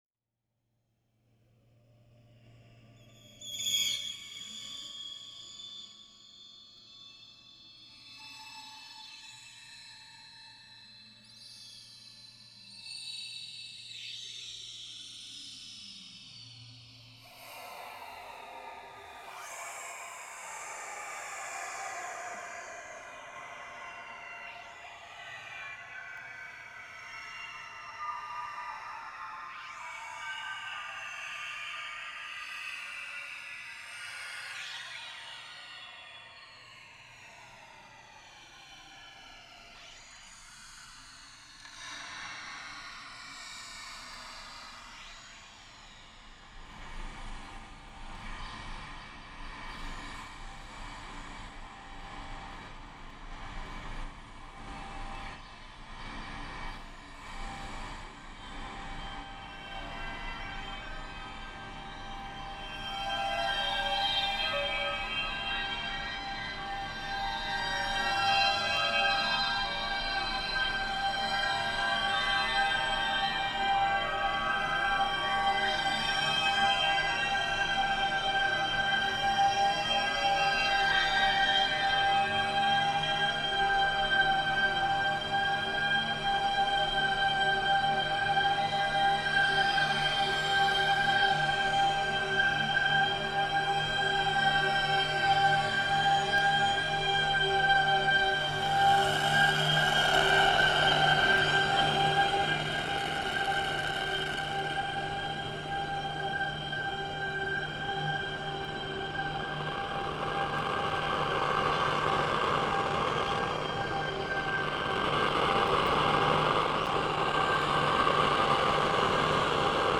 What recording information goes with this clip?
We had a short rehearsal prior to the performance. laptop One thing: the recordings are only of the processed output from my laptop. None of the original koto sound is present.